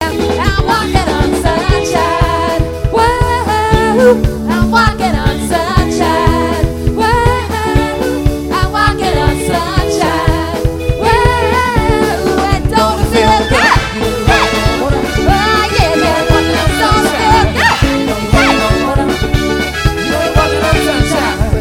PLAYING LIVE!!!!